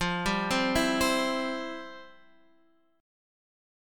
FM7sus2 chord